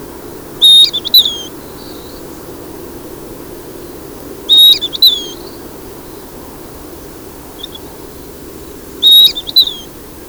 jui-madrugada.wav